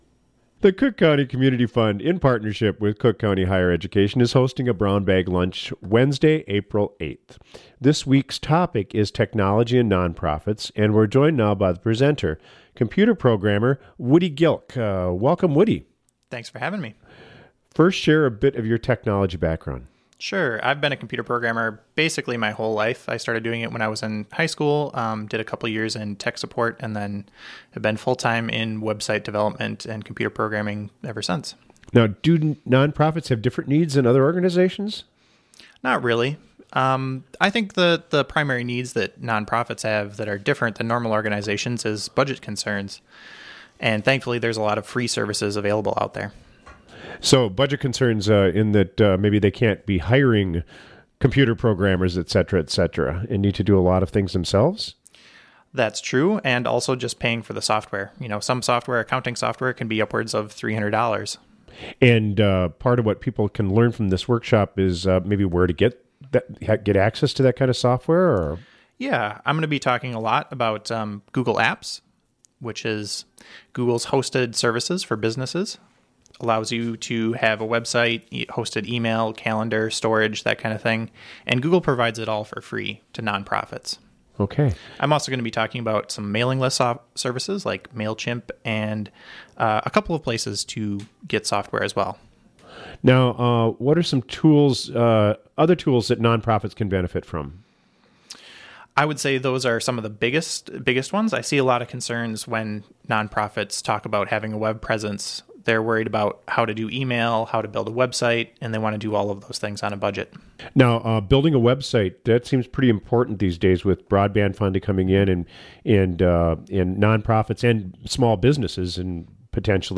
'Technology and Nonprofits' is April 8 Brown Bag Lunch topic | WTIP North Shore Community Radio, Cook County, Minnesota